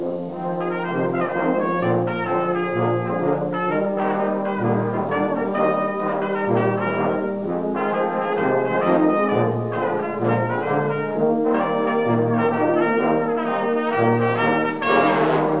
Home ] What is a Brass Band?